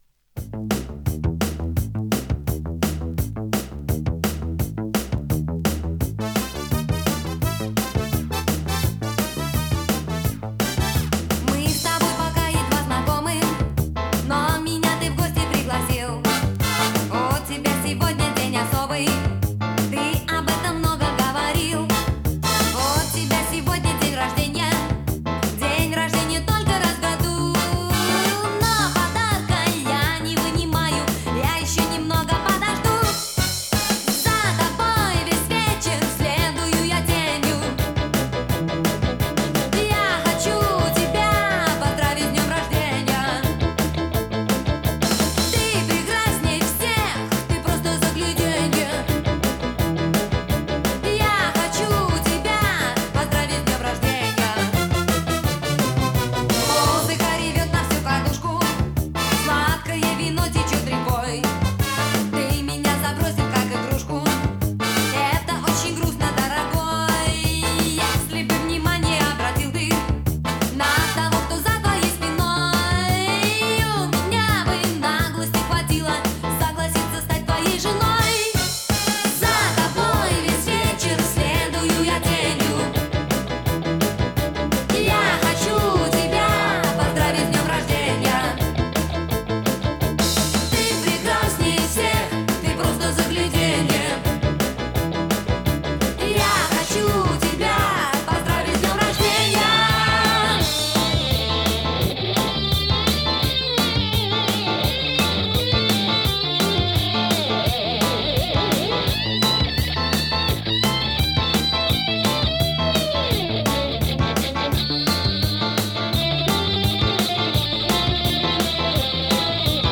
Жанр: Pop, Schlager